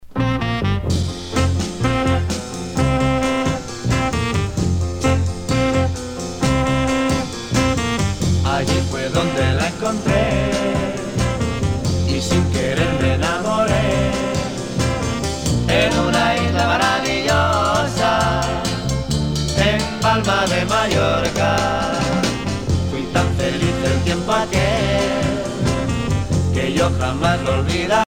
danse : madison